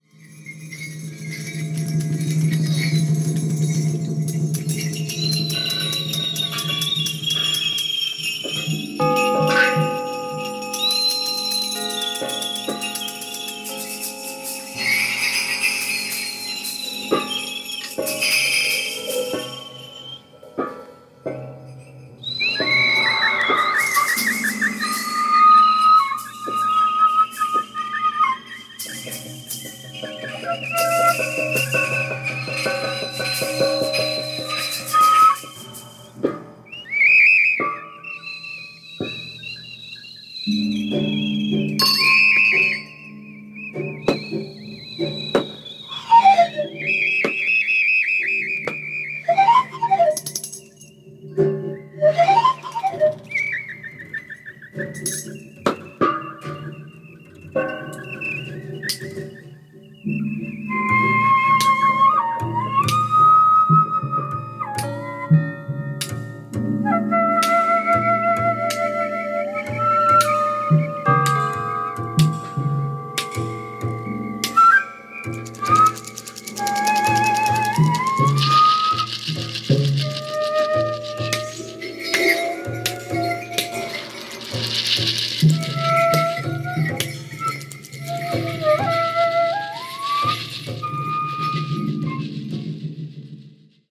ご機嫌な仲間とシカゴで録音されたアルバムです。